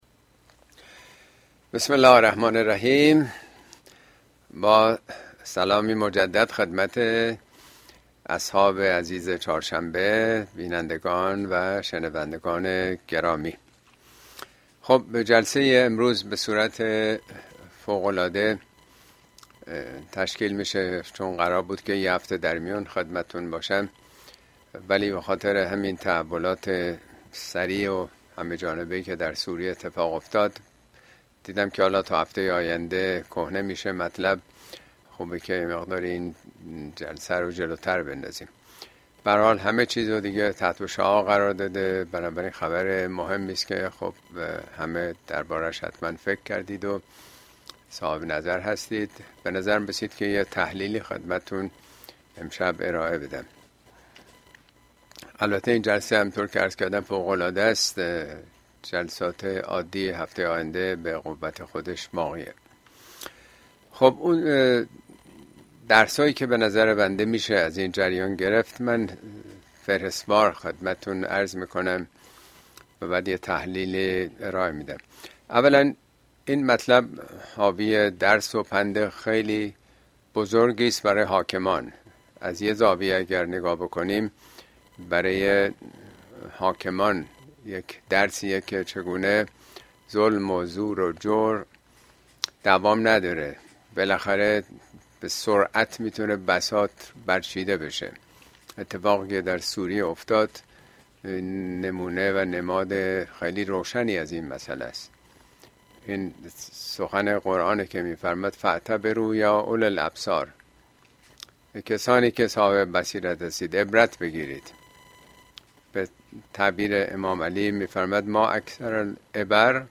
` موضوعات اجتماعى اسلامى سوريه و ايران، شباهت‌ها و تفاوت‌ها اين سخنرانى به تاريخ ۱۱ دسامبر ۲۰۲۴ در كلاس آنلاين پخش شده است توصيه ميشود براىاستماع سخنرانى از گزينه STREAM استفاده كنيد.